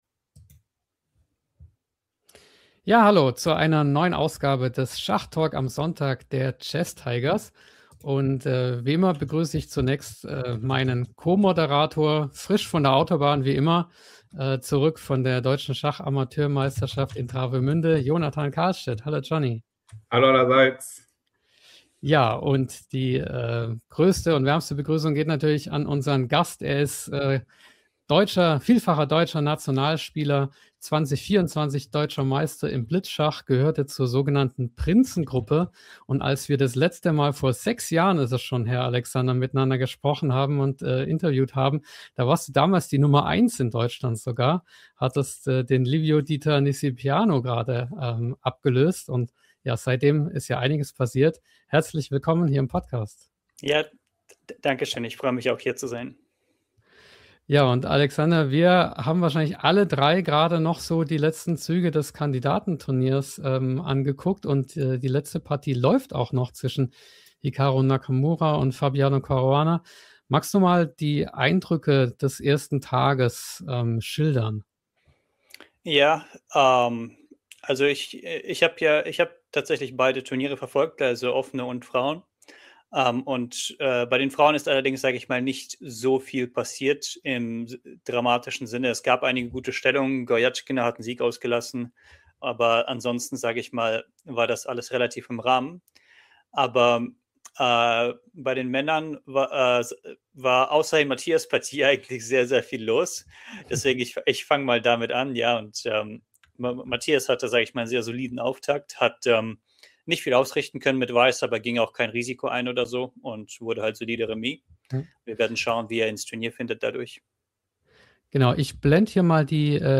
Er enthält Tipps zur Verbesserung sowie Interviews mit Prominenten aus der Schachwelt.